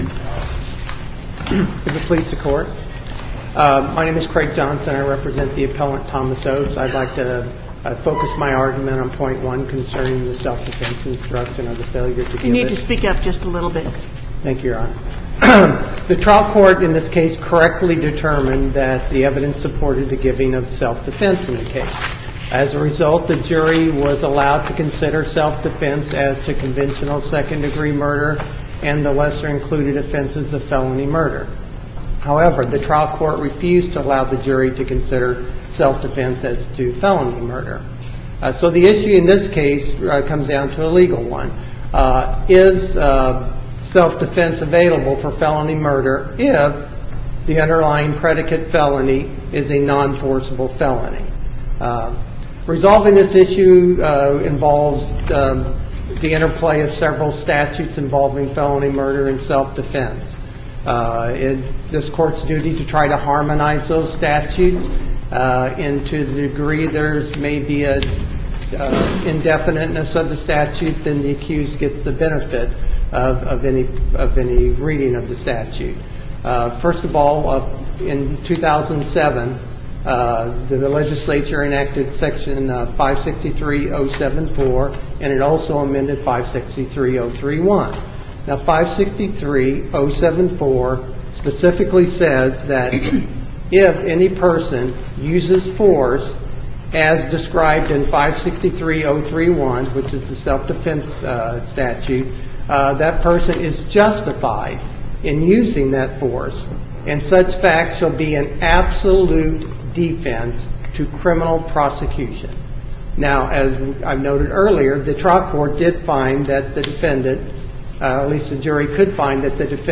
Constitutional validity of and ability to comply with health care affidavit requirement Listen to the oral argument